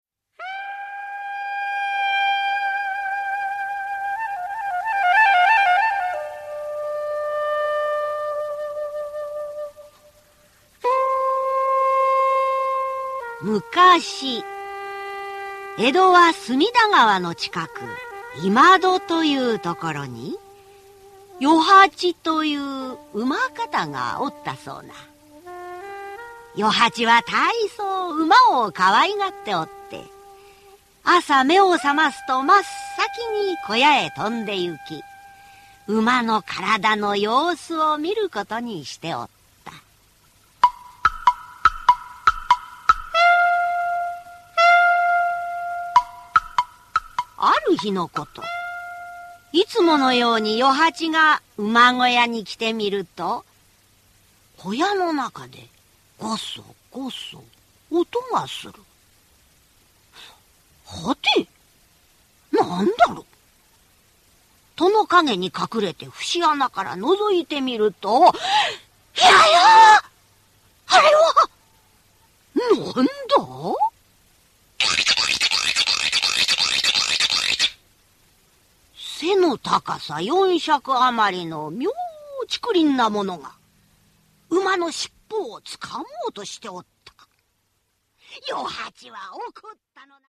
[オーディオブック] 馬のけをぬくかっぱ